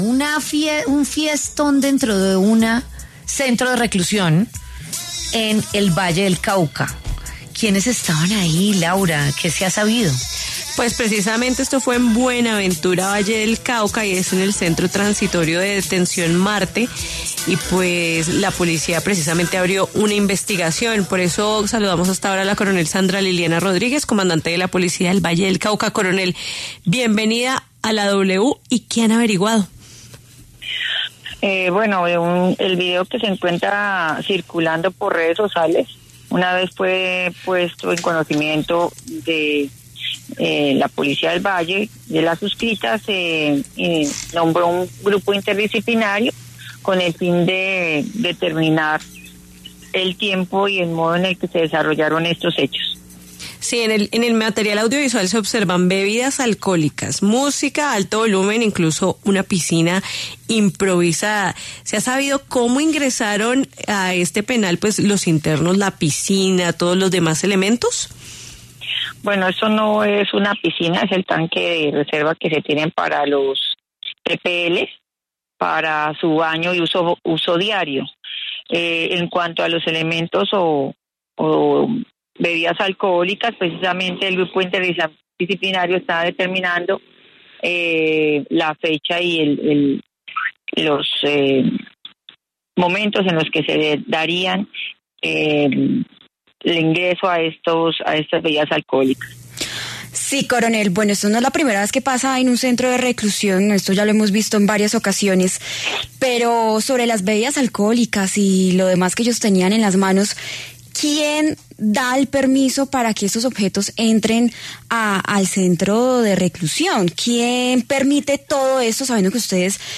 La coronel Sandra Liliana Rodríguez, comandante de la Policía del Valle del Cauca, habló en La W sobre el video que se viralizó de una fiesta con piscina y licor en un centro de detención de Buenaventura.